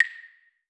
Percussion #16.wav